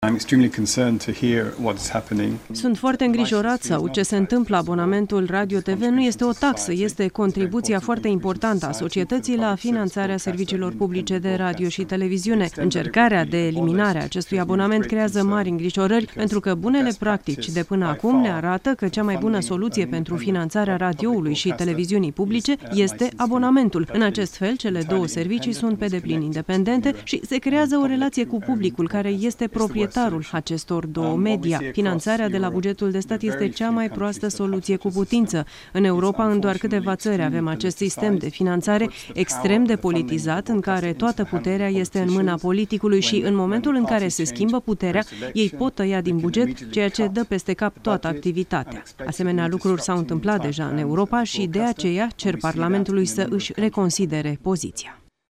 Într-o declarație pentru colegii de la Televiziunea Românã